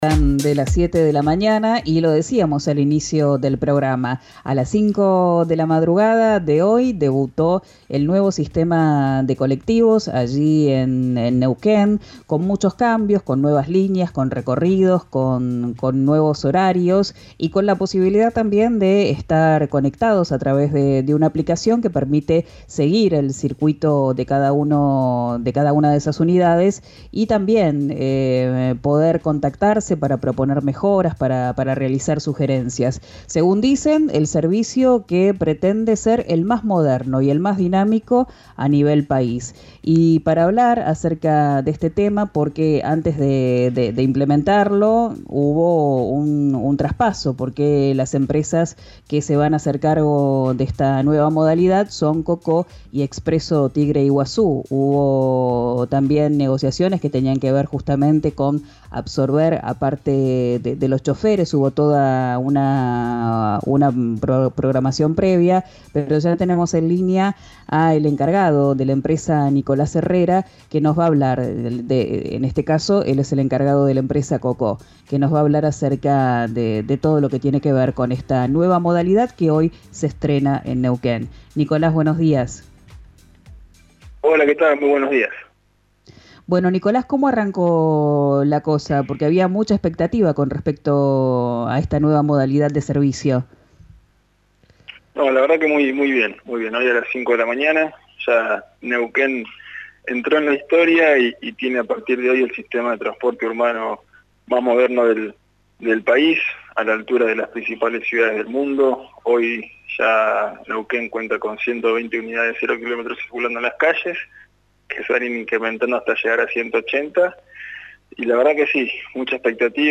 en diálogo con «Arranquemos” de RÍO NEGRO RADIO.